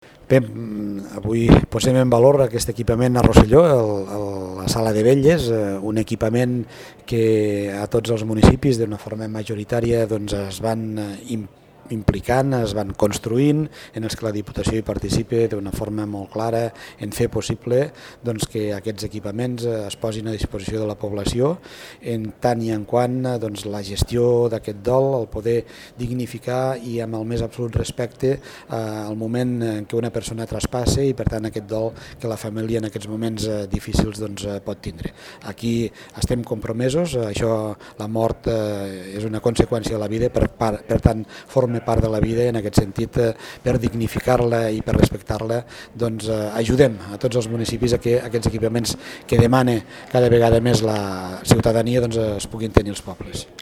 Declaracions-Sr.-Joan-Re----.mp3